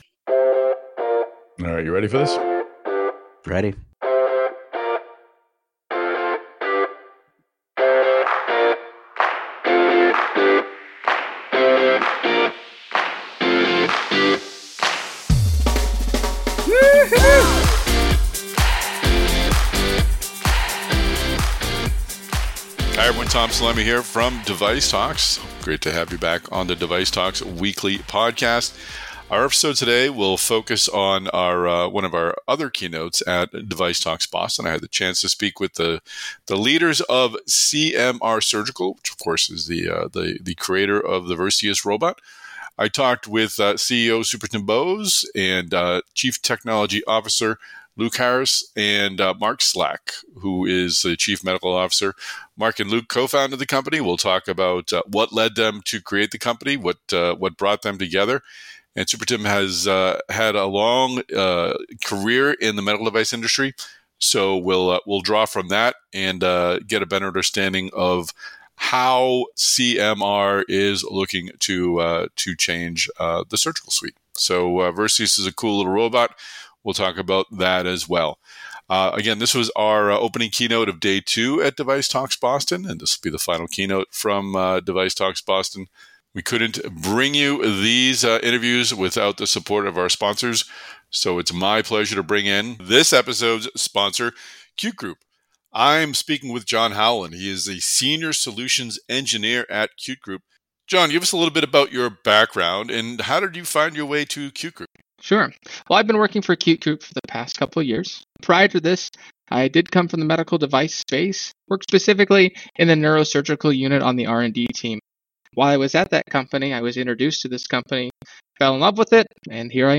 In this episode of the DeviceTalks Weekly Podcast, we’ll replay our Day 2 opening keynote at DeviceTalks Boston with three senior leaders from CMR Surgical, creator of the versatile Versius surgical robotic systems.